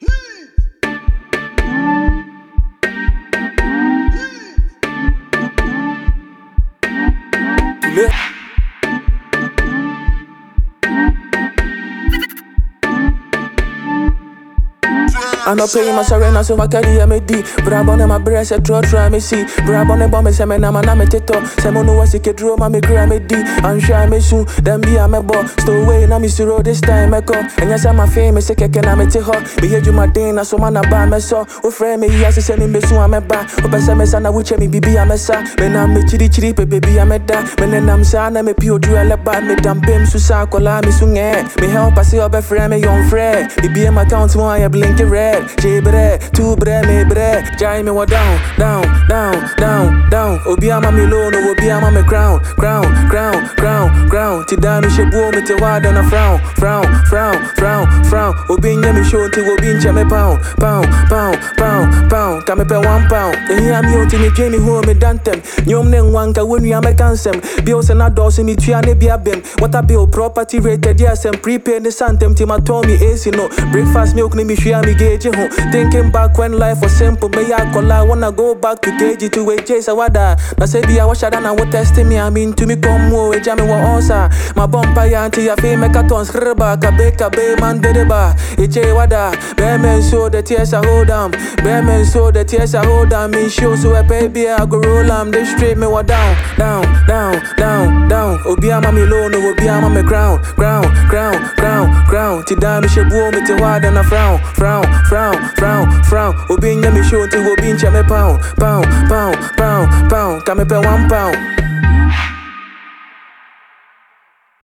a young, talented Ghanaian rapper